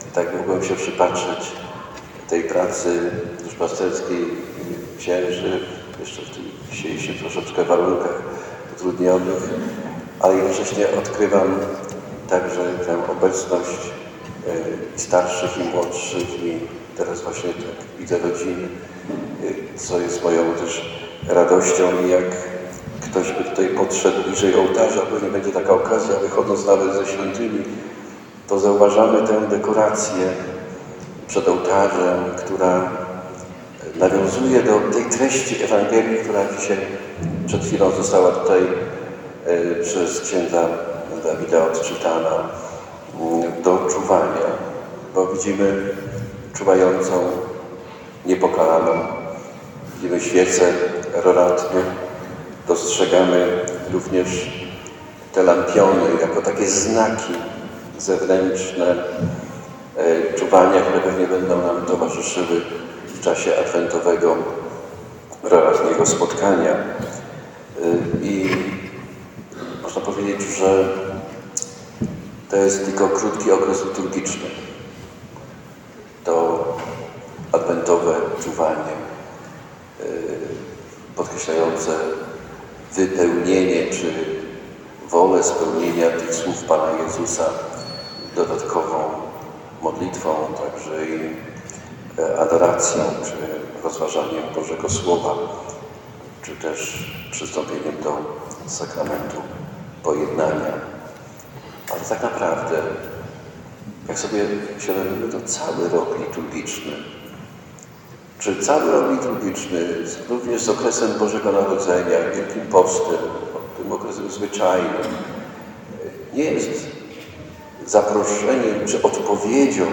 W I Niedzielę Adwentu 3 grudnia po godzinie 11:30 odbyła się Msza Święta Kanoniczna, kończąca wizytację parafii.
Wygłosił okolicznościowe kazanie (można je wysłuchać poniżej).